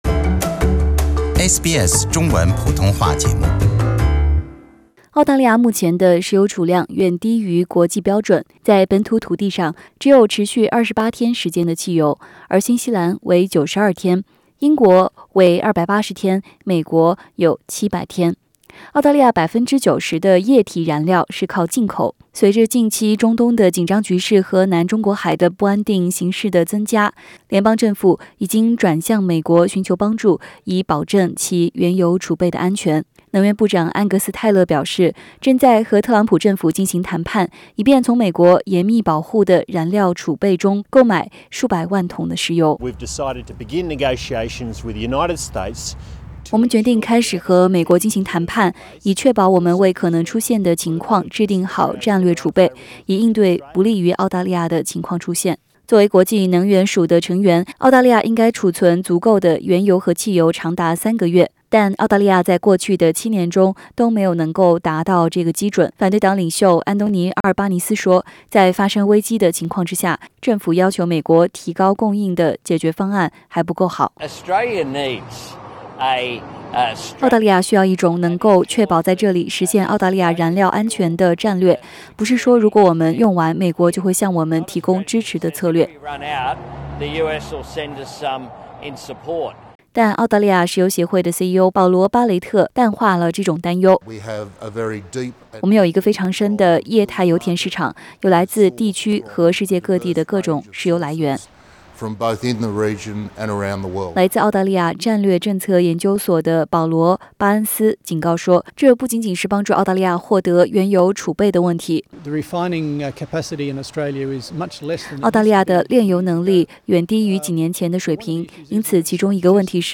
Source: AAP SBS 普通话电台 View Podcast Series Follow and Subscribe Apple Podcasts YouTube Spotify Download (5.03MB) Download the SBS Audio app Available on iOS and Android 联邦政府正在与美国进行谈判，以便在紧急情况下购买其石油以增加澳大利亚的储备。